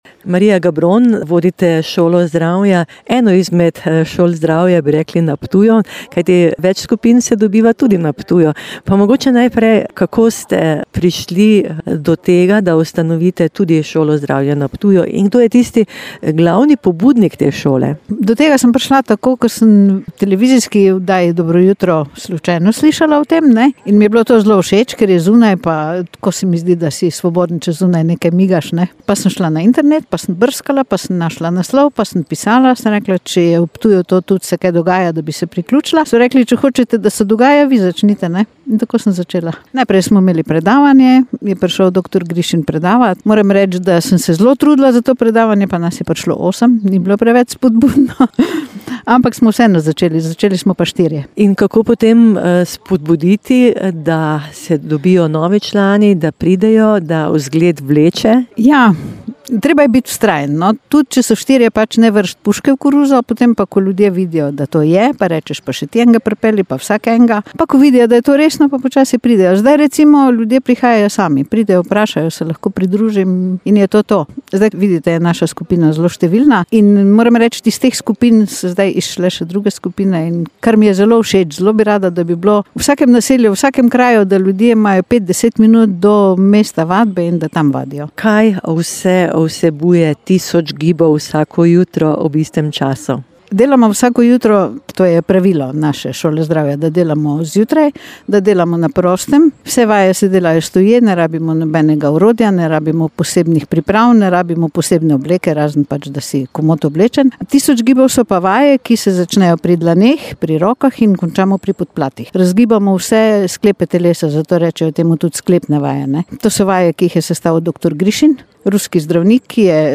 "Jesen življenja" - oddaja ob 18. uri, pogovor z